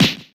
slap.wav